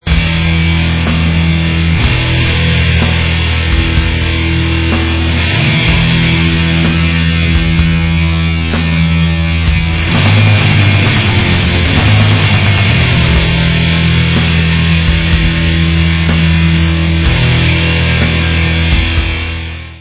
+ 15 YEARS OF SATANIC BLACK METAL // 2008 ALBUM